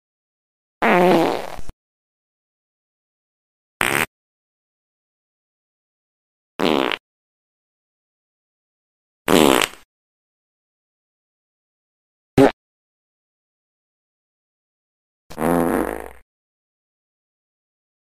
True Fart